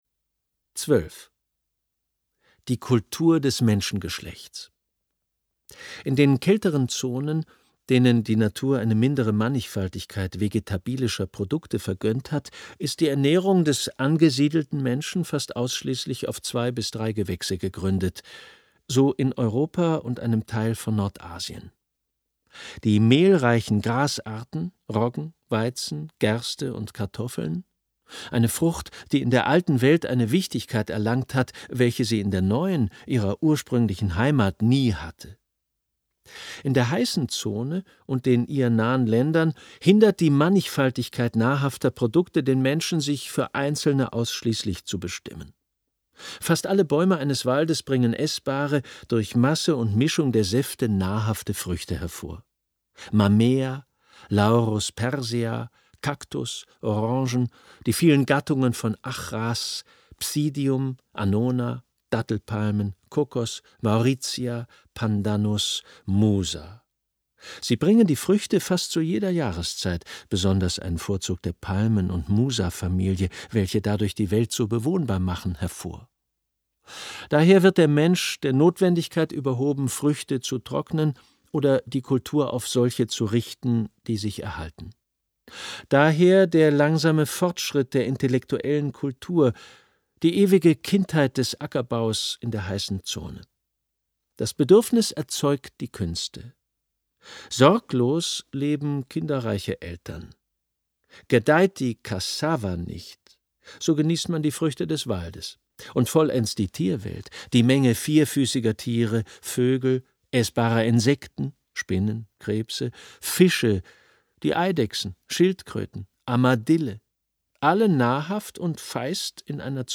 Schlagworte Amerikanische Reise • Ethnologie • Hörbuch • Humboldt • Kulturen • Lesung • Sprachen • Ungekürzt